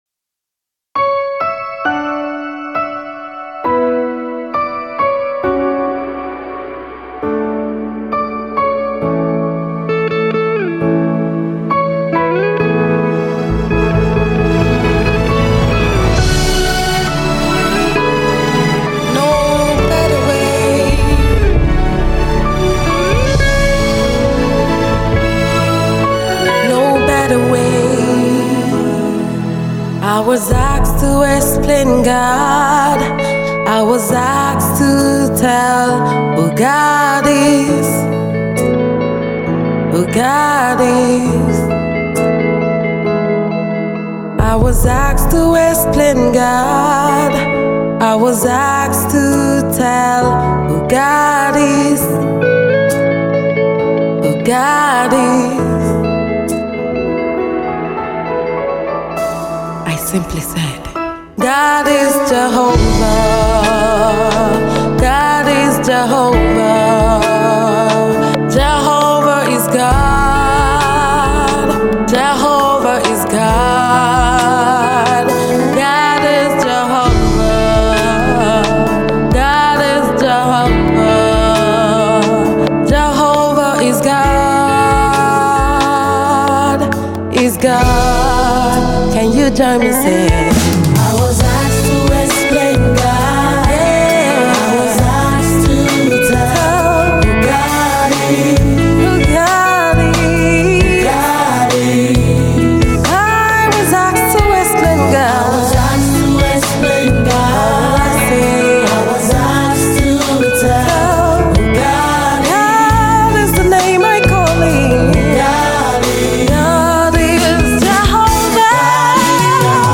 Nigerian dynamic gospel minister and exquisite songwriter